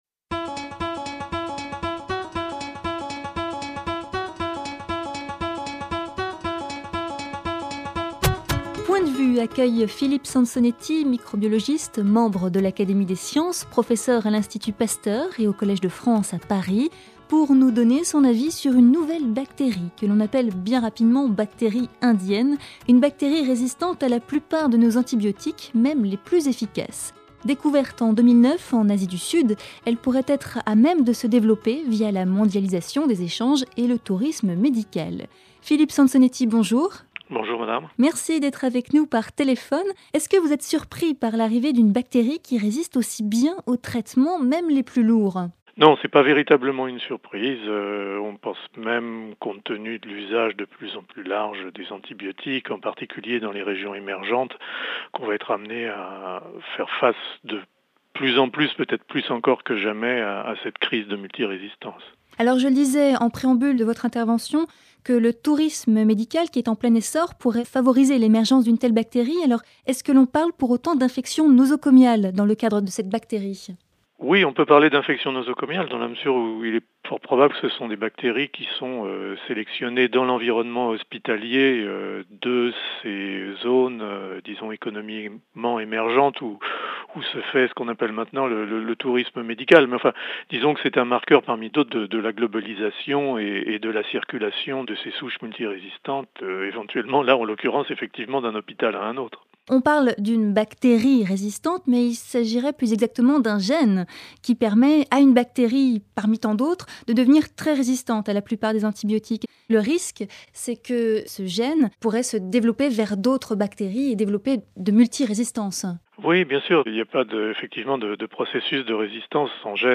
Découverte en 2009 en Asie du sud, une bactérie résistante à la plupart de nos antibiotiques même les plus efficaces pourrait être à même de se développer via la mondialisation des échanges et le tourisme médical. Philippe Sansonetti, microbiologiste, membre de l’Académie des sciences, professeur à l’Institut Pasteur et au Collège de France, nous livre son point de vue.